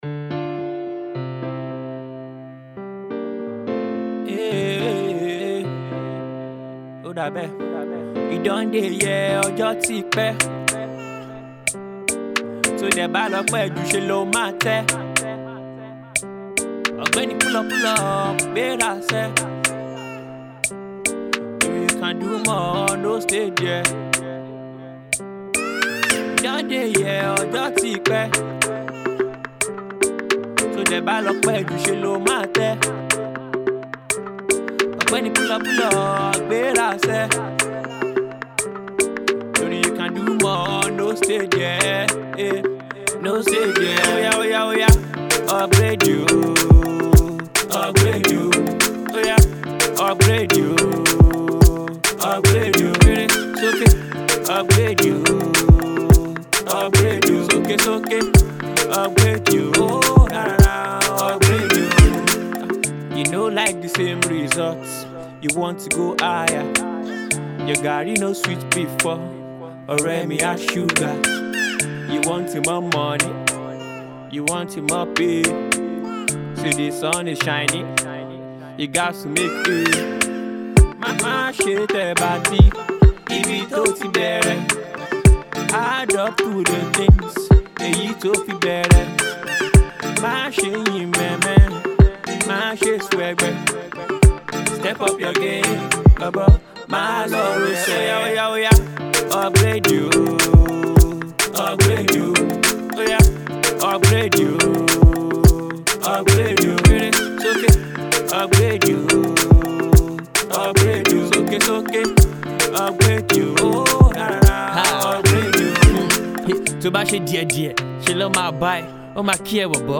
Well known for giving the best of Afro Urban.